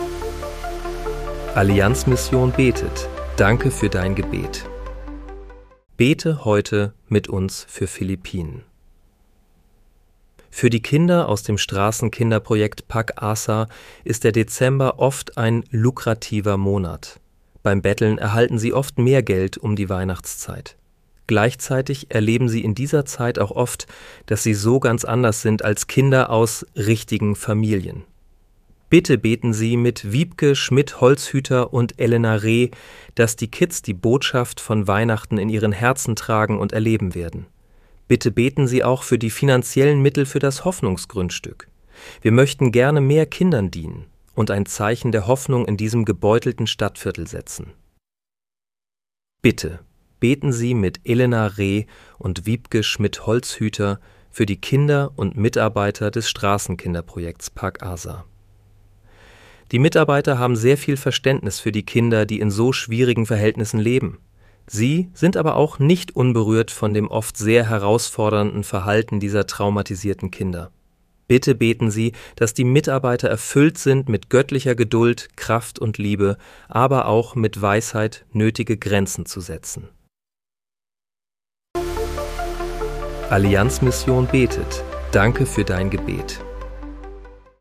Bete am 03. Dezember 2025 mit uns für Philippinen. (KI-generiert